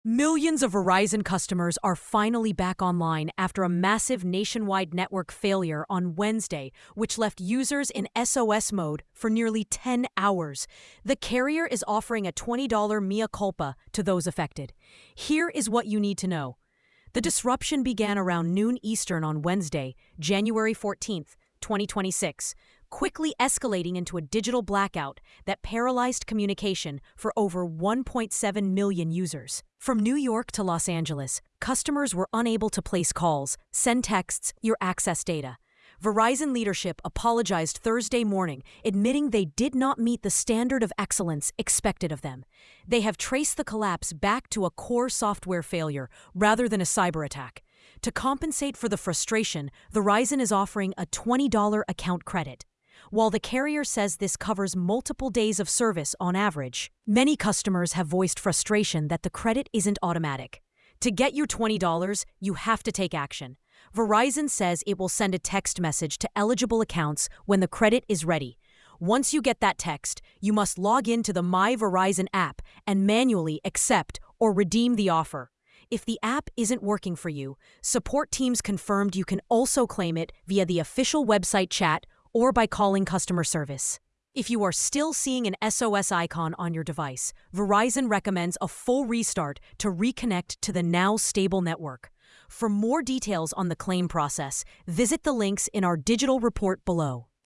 AI Generated